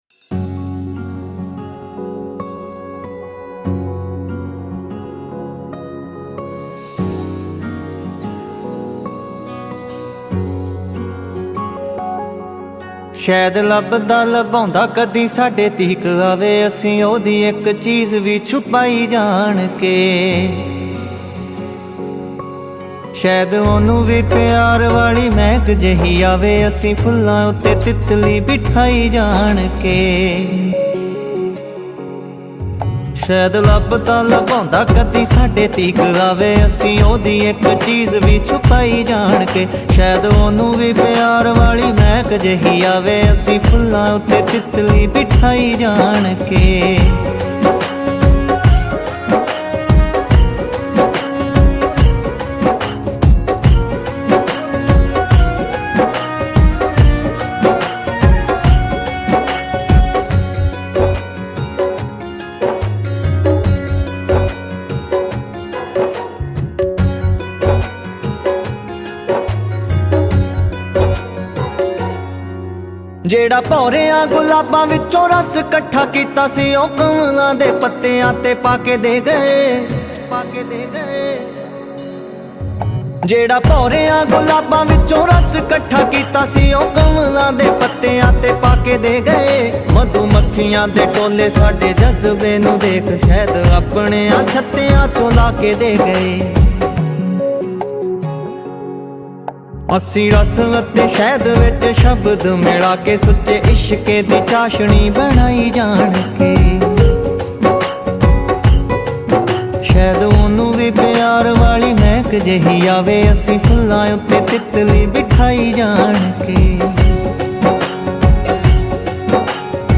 Latest Punjabi Song 2022 | New Romantic Song